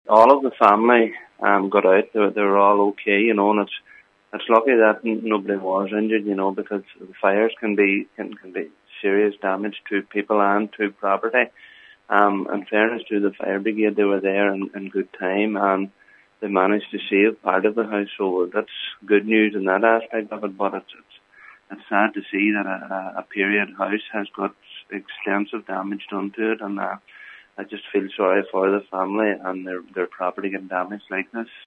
Local Cllr Paul Canning said the quick reaction from the fire service ensured no-one was injured and part of the house could be saved: